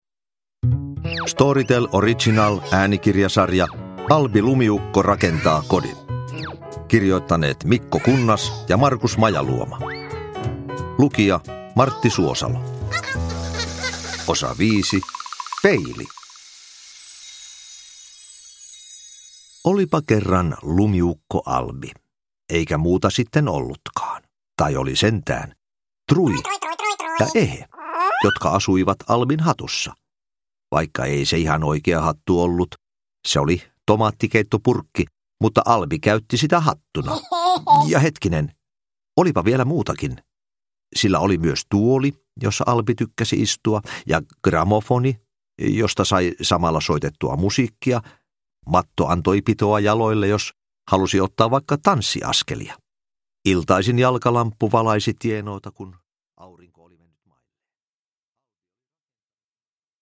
Albi rakentaa kodin: Peili – Ljudbok – Laddas ner
Uppläsare: Martti Suosalo